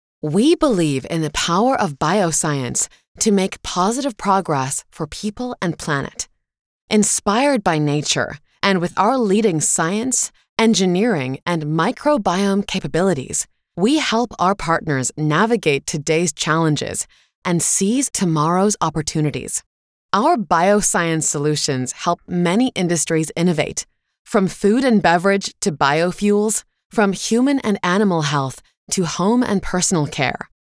Bioscience at IFF – Locução em Inglês para vídeo corporativo